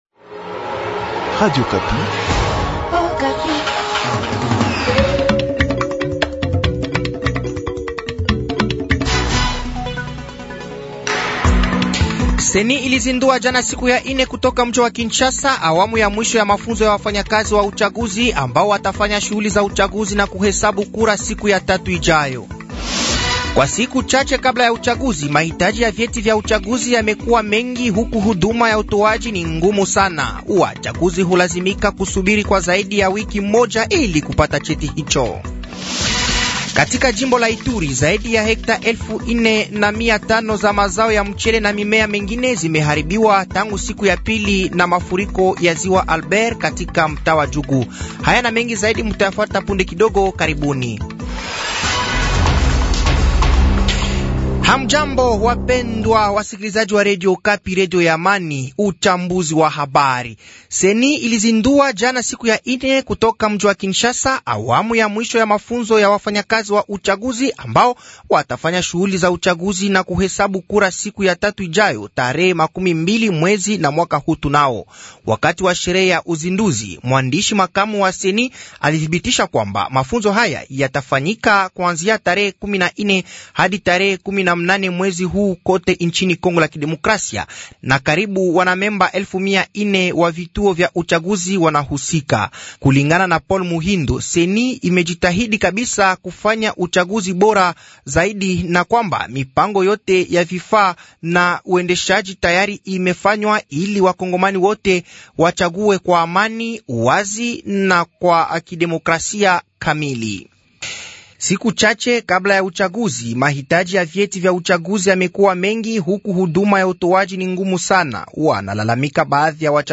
Journal matin
Habari za siku ya tano asubuhi tarehe 15/12/2023